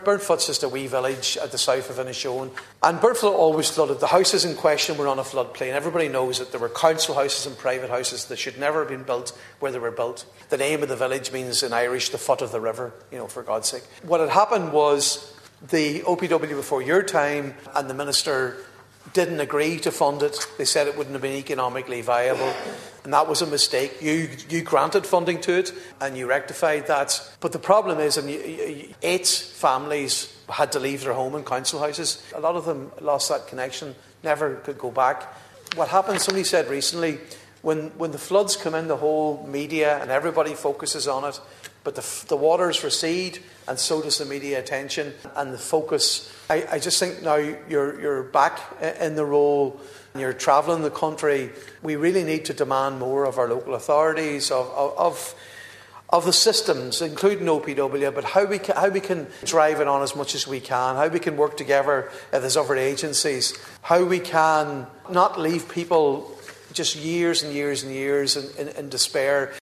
During statements on flooding in Leinster House, Deputy Padraig Mac Lochlainn acknowledged that during his first tenure in the position, Minister Kevin Boxer Moran announced funding for Burnfoot, with a scheme to go to planning shortly.